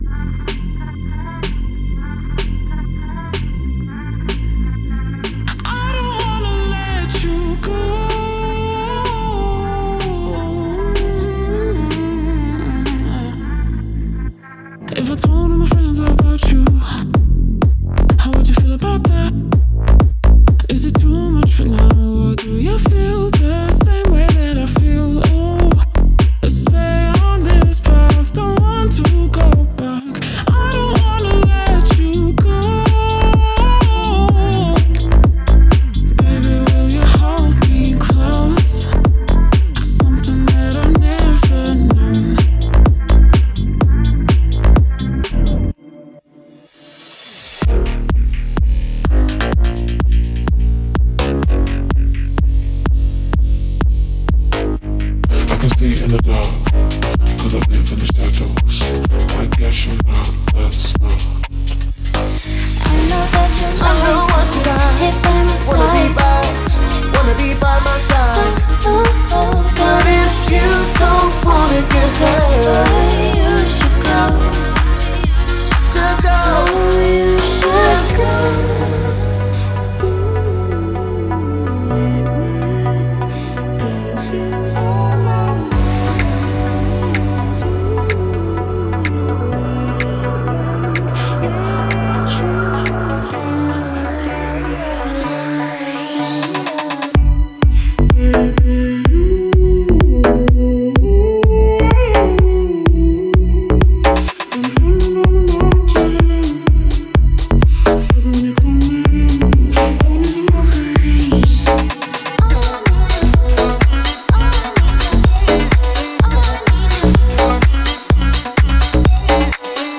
3. EDM